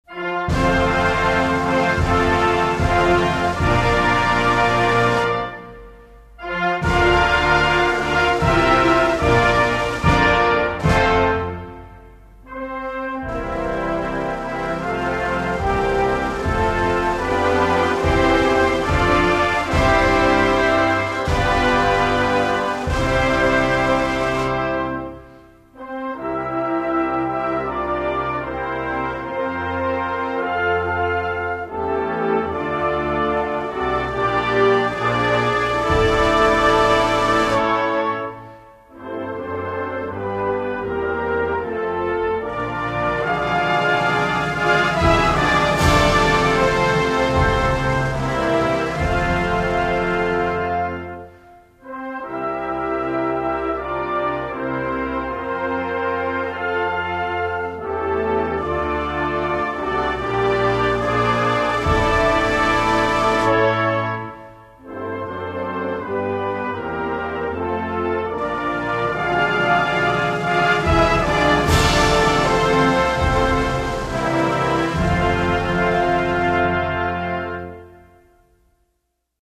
Инструментальная музыка Дании